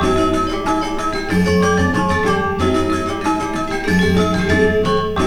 GAMELAN 6.wav